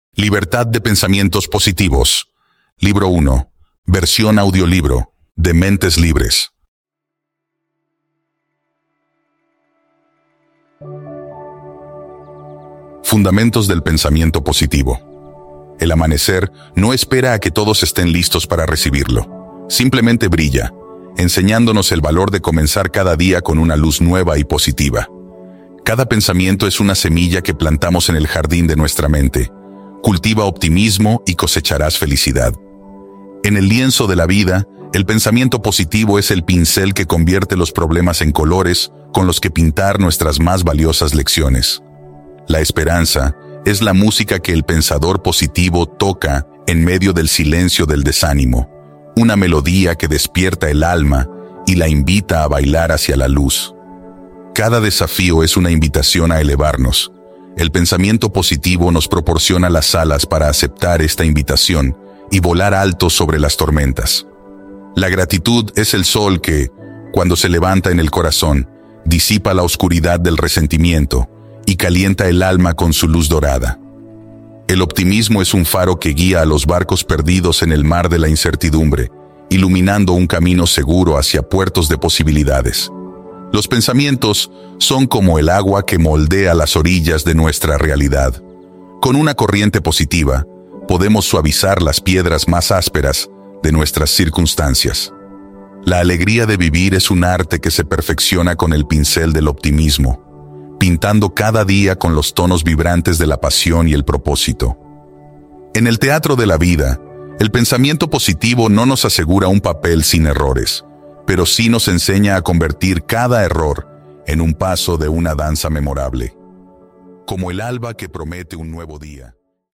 AUDIOLIBRO 1: LIBERTAD DE PENSAMIENTOS POSITIVOS
Disfruta de 19 horas de narración increíble!
DEMO-AUDIOLIBRO-1-LIBERTAD-DE-PENSAMIENTOS-POSITIVOS.mp3